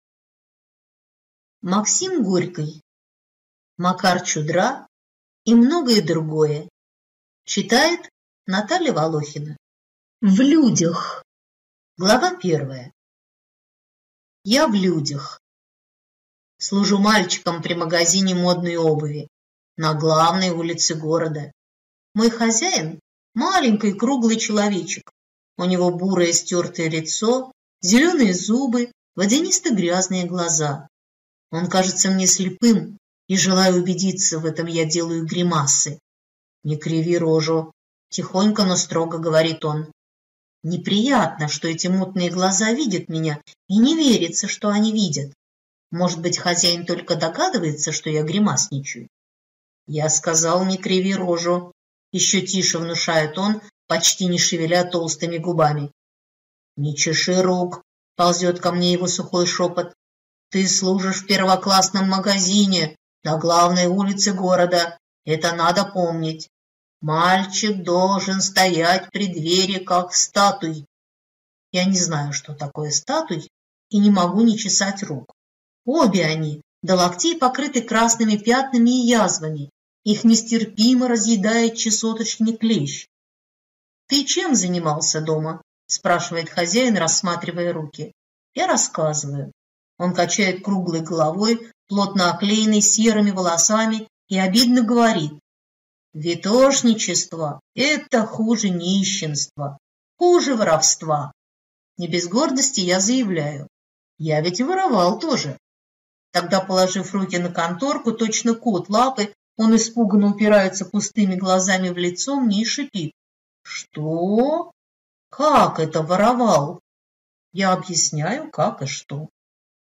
Aудиокнига Макар Чудра и многое другое…